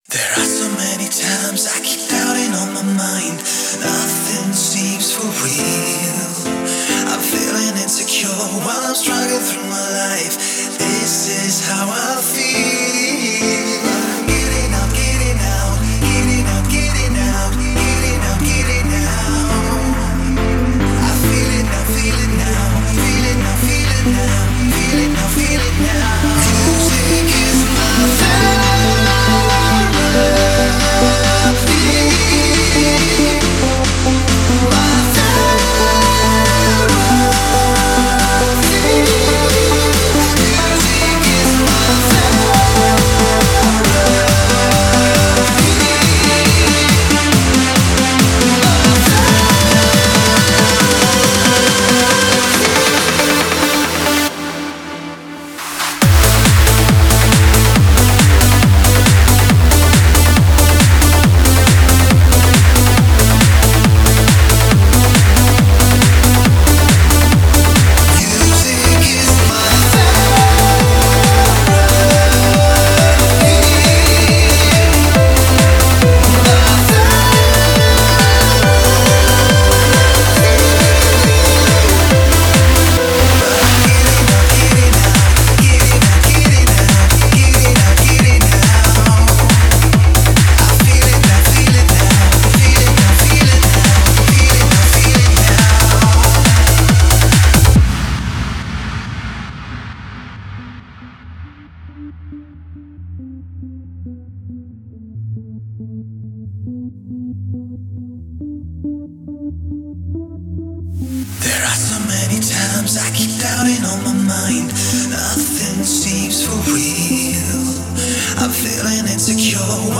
это энергичная композиция в жанре trance